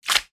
splat1.mp3